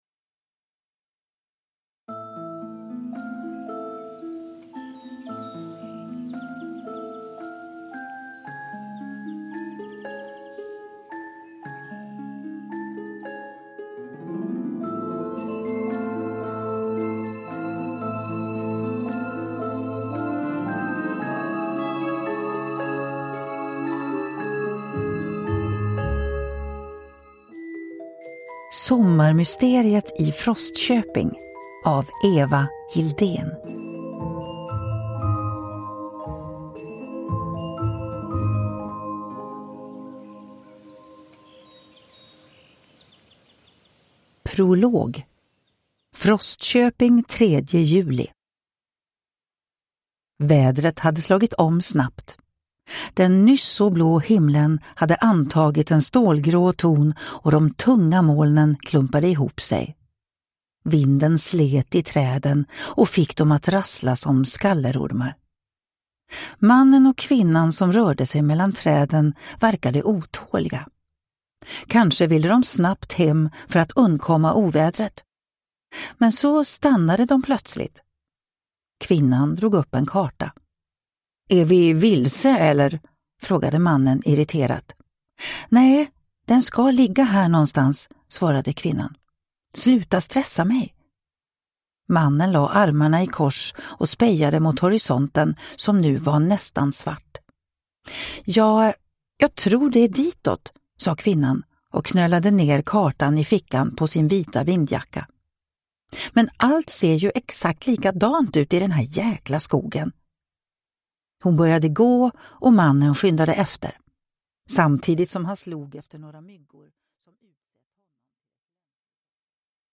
Sommarmysteriet i Frostköping 1 (ljudbok) av Eva Hildén